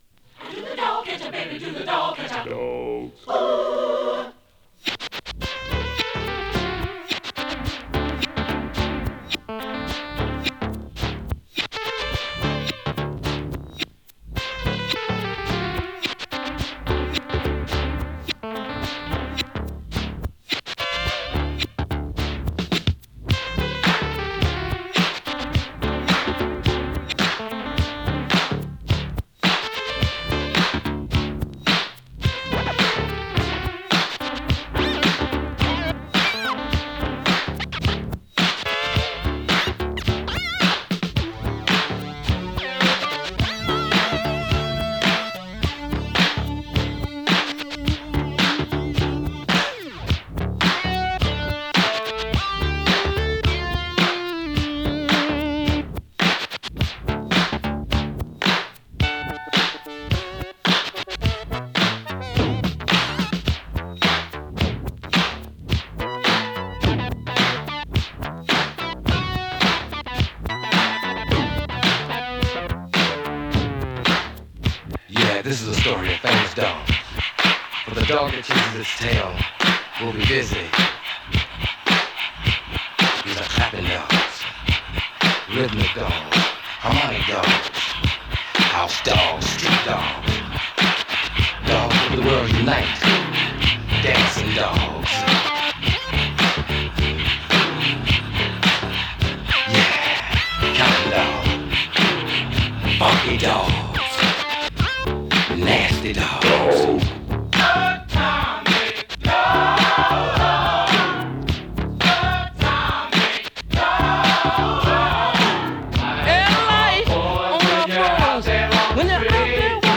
サンプリングねた定番のトボケたP-Funkクラシック！